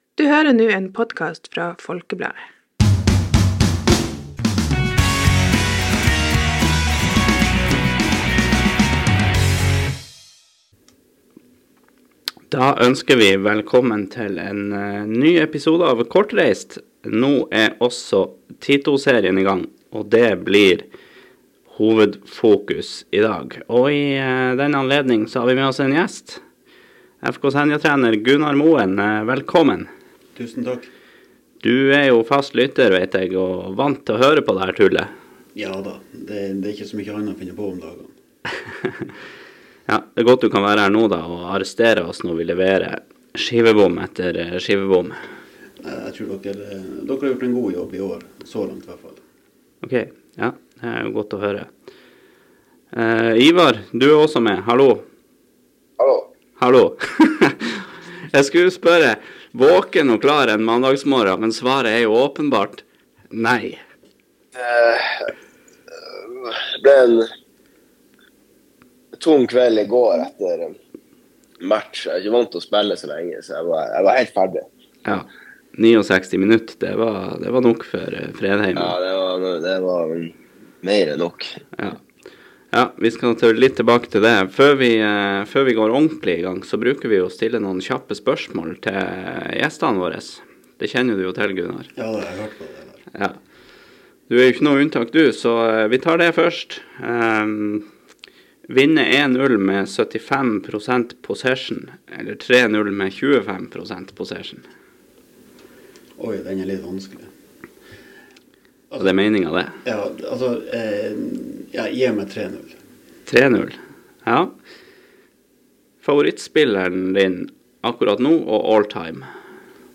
i studio